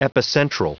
Prononciation du mot epicentral en anglais (fichier audio)
Prononciation du mot : epicentral